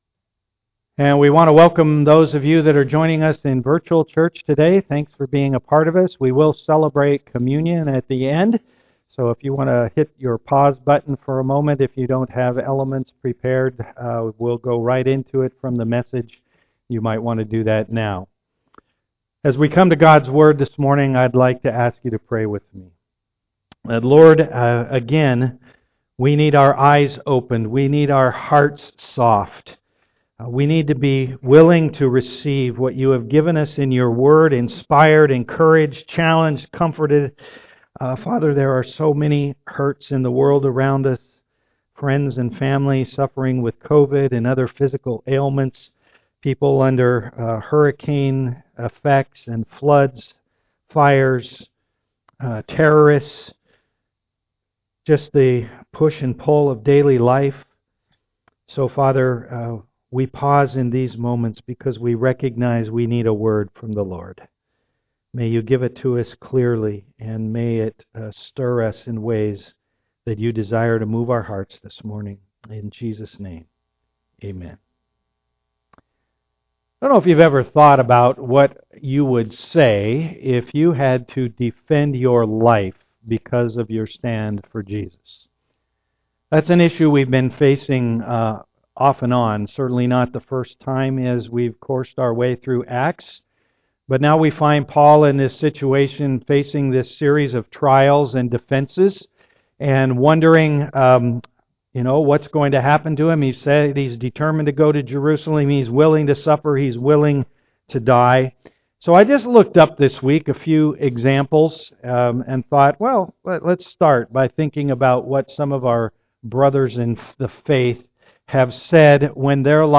Acts 22:30-23:35 Service Type: am worship Today we will celebrate communion together at the end of the message.